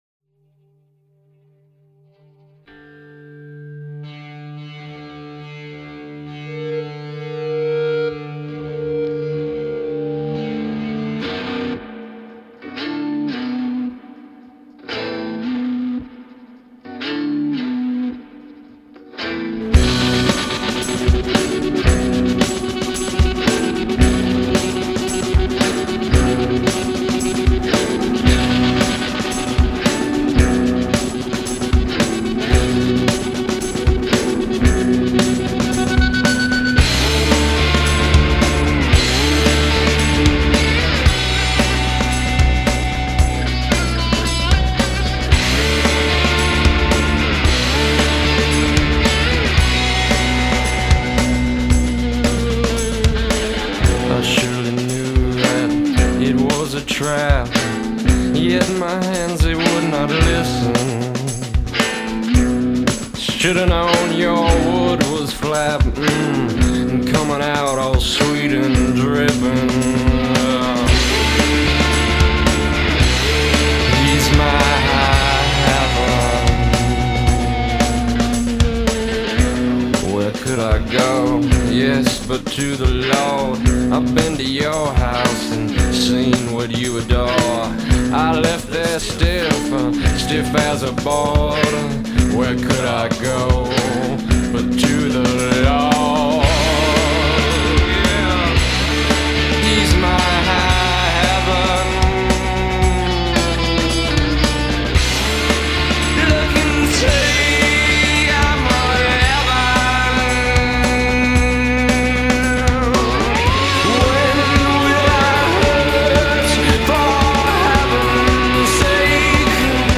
import.  15 dark rockin' Country tunes.